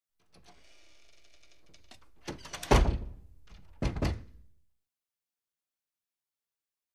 Basic Wood Door Open With Creaks And Double Close